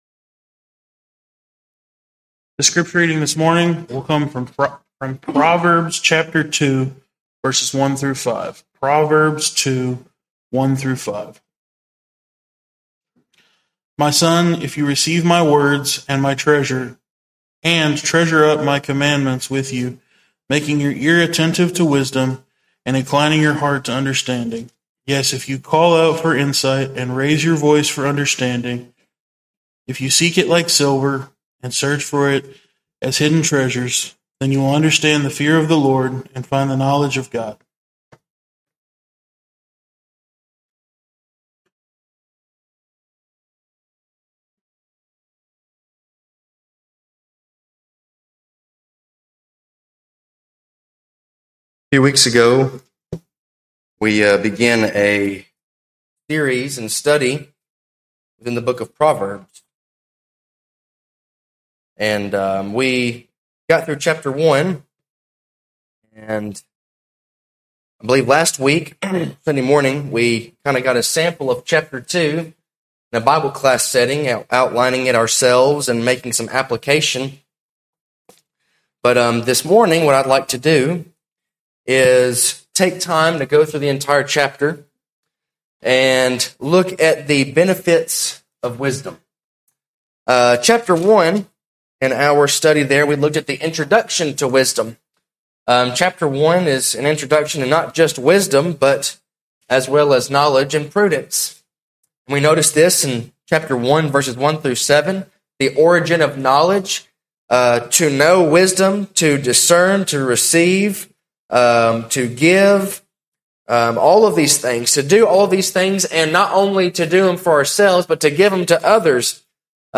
The Book of Proverbs – Benefits of Wisdom - Mabank church of Christ